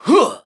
One of Ludwig's voice clips in New Super Mario Bros. Wii